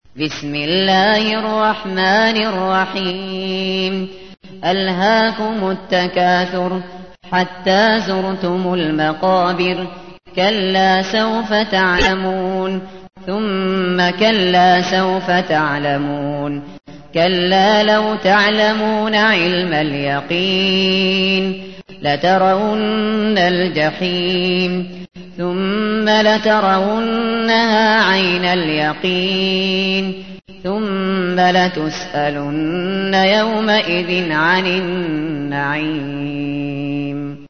تحميل : 102. سورة التكاثر / القارئ الشاطري / القرآن الكريم / موقع يا حسين